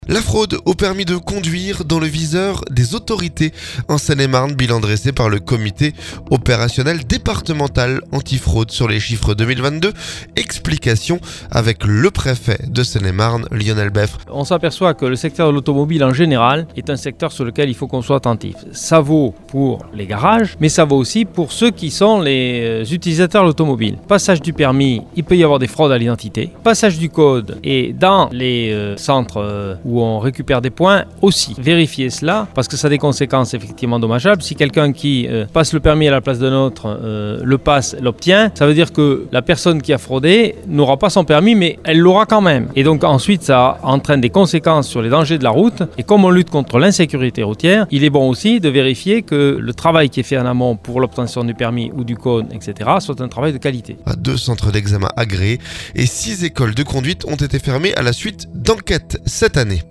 La fraude au permis de conduire dans le viseur des autorités en Seine-et-Marne. Bilan dressé par le Comité opérationnel départemental anti fraude sur les chiffres 2022. Explications du préfet de Seine-et-Marne, Lionel Beffre.